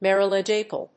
• IPA(key): /ˌmɪəɹiəˈlɒd͡ʒɪkəl/